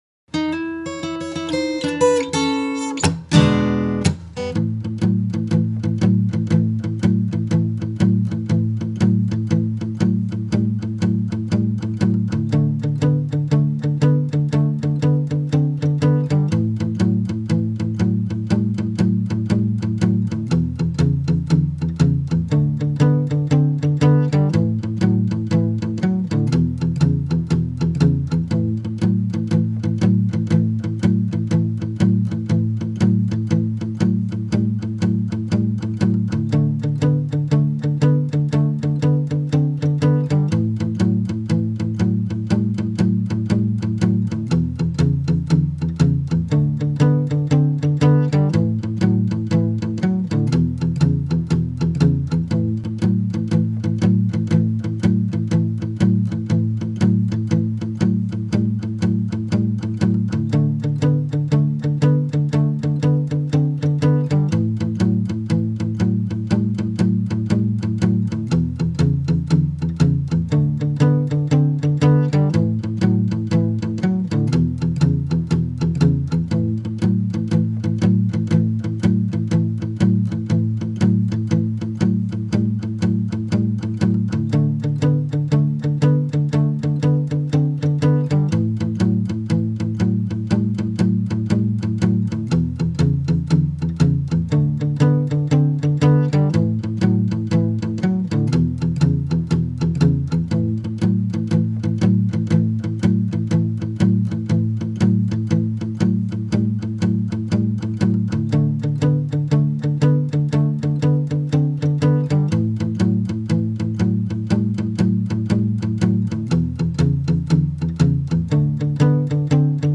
12-bar Blues in A - This is a 12 bar blues shuffle in the key of A. Practice
Blues Rhythm Jam Track in A II.mp3